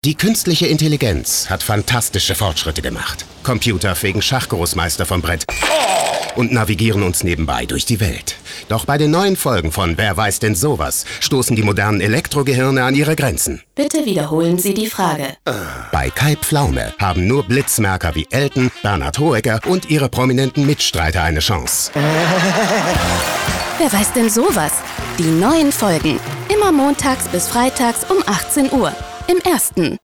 Werbung: Wer weiß denn sowas? (Giesing Team)